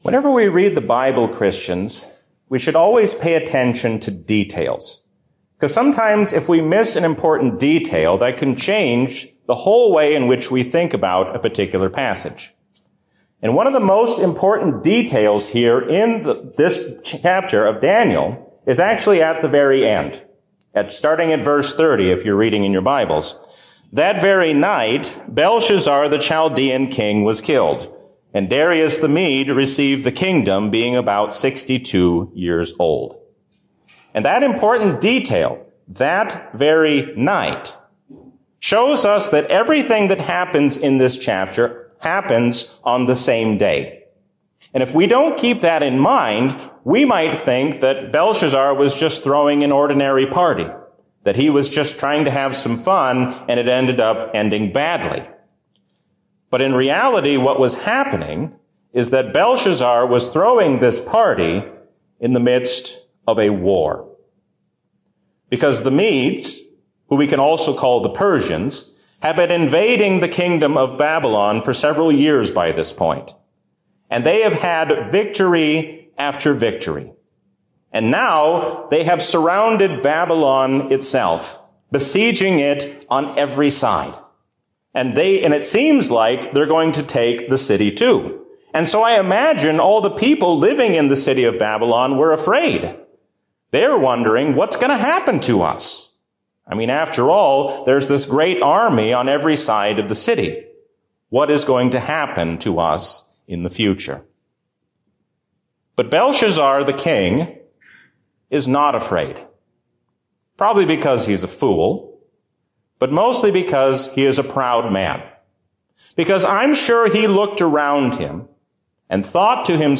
A sermon from the season "Epiphany 2022." Do not lose heart, because God is the Master of all our ways.